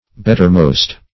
bettermost - definition of bettermost - synonyms, pronunciation, spelling from Free Dictionary Search Result for " bettermost" : The Collaborative International Dictionary of English v.0.48: Bettermost \Bet"ter*most`\, a. Best.